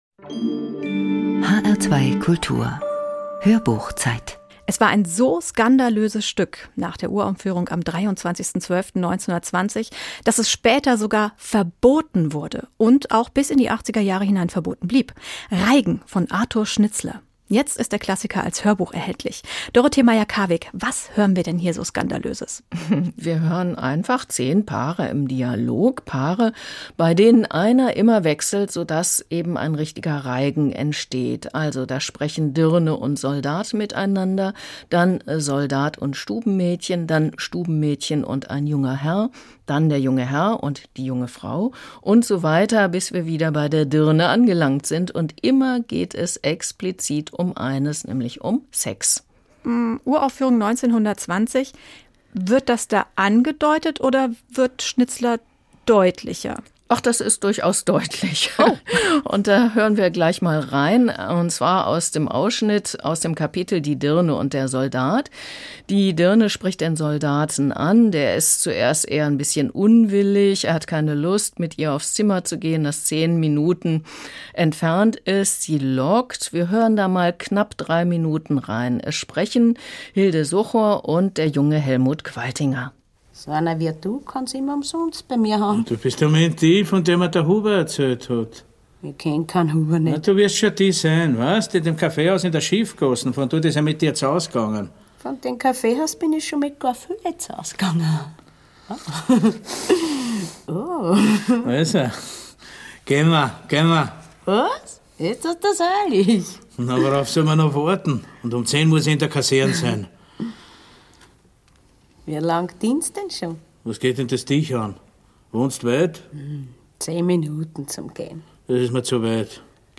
DetailsArthur Schnitzler: Reigen - inszenierte Lesung 00:07:32